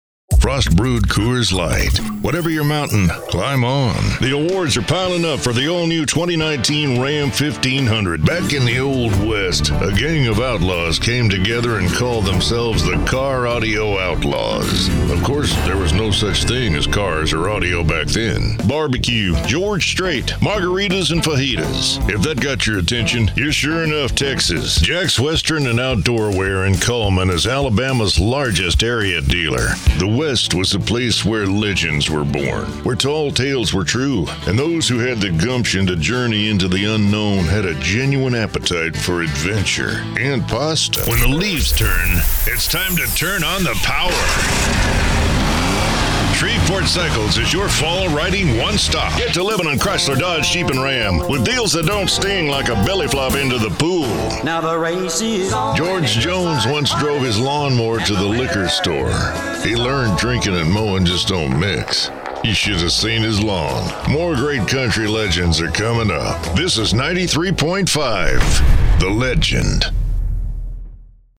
Smooth, warm, conversational, authentic and inviting, yet capably diverse
Authentic Texan
Middle Aged
I have a great home studio with Neumann mic, Pro Tools, isolated vocal booth and Source Connect!!